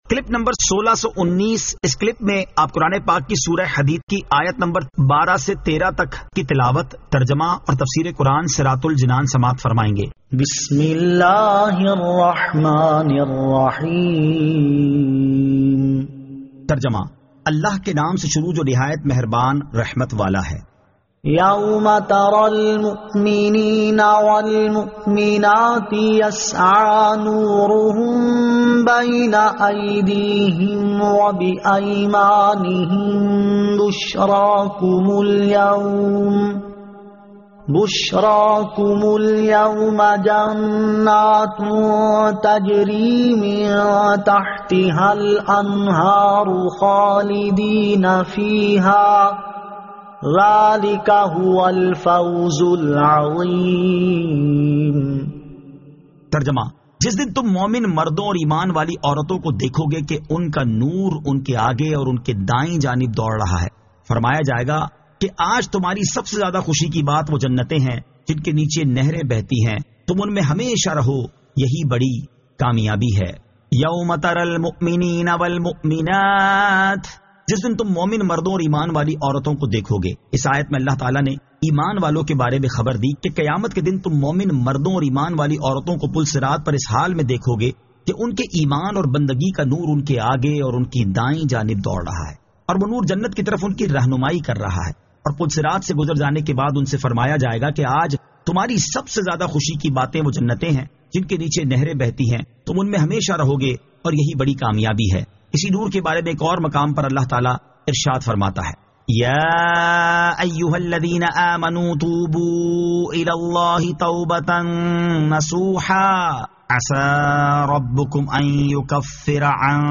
Surah Al-Hadid 12 To 13 Tilawat , Tarjama , Tafseer